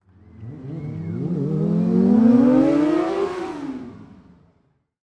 Motorcycle Drive-By Fast 2